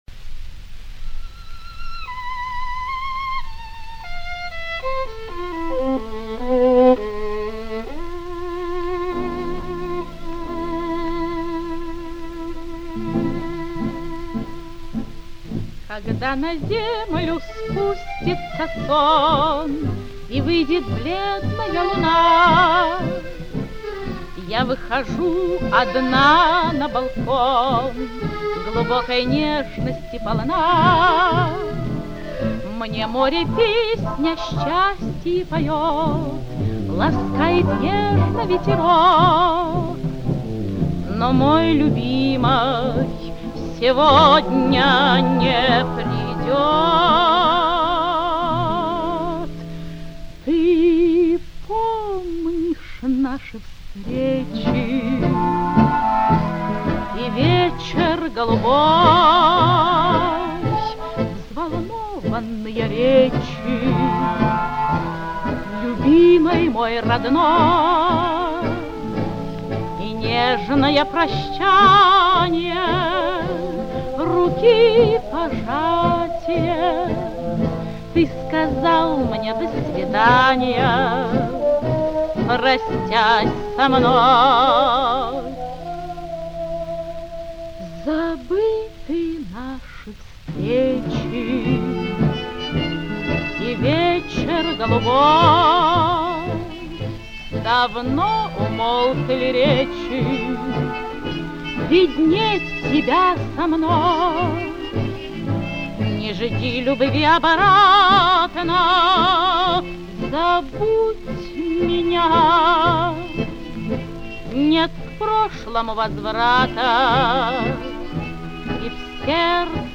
Джаз-орк. под упр.